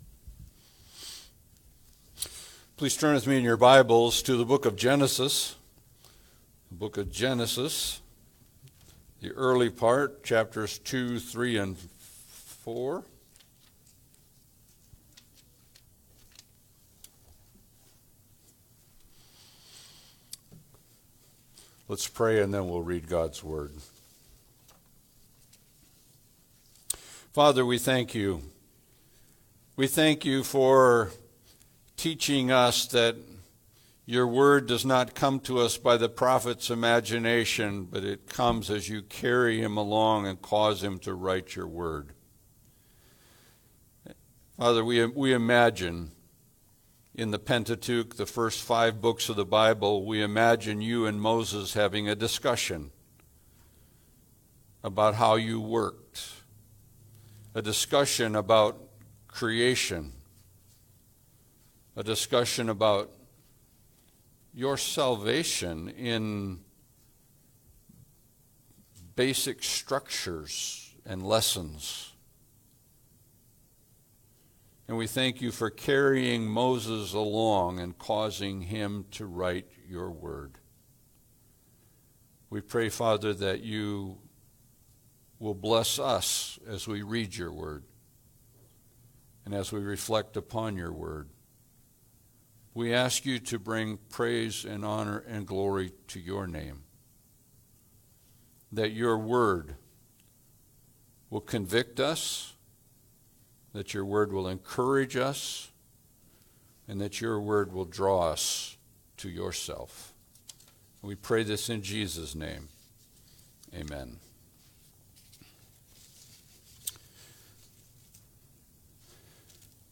Passage: Genesis 2:15-17, 3:1-20, 4:1-8 Service Type: Sunday Service